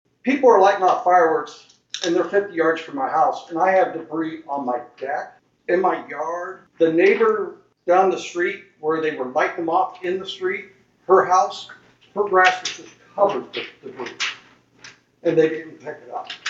Atlantic CC meeting, 7-16-25
A citizen of Atlantic voiced his displeasure with the way some of his neighbors have been using fireworks, and the resulting debris left on his property, along with the danger of a fire starting on his outdoor furniture and deck caused by the reckless use of fireworks.